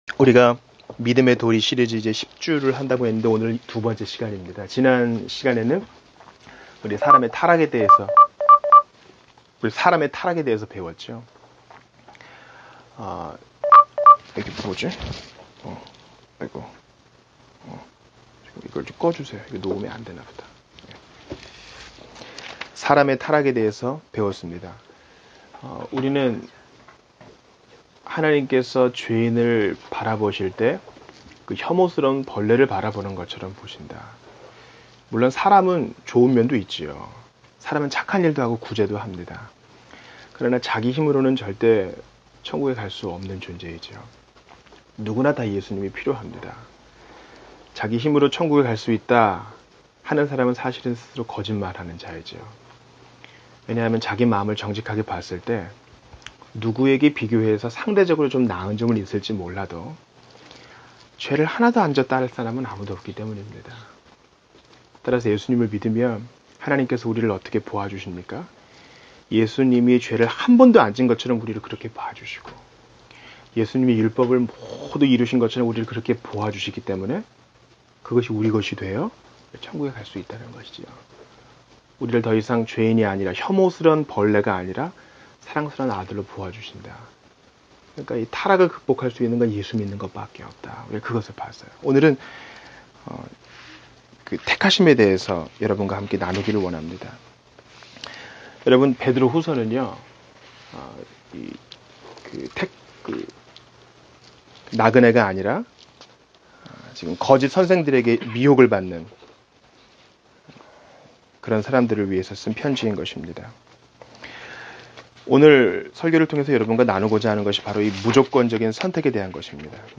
2014년 5월 25일 주일설교(베드로후서 1:10-11)"확신할 수 있는 선택"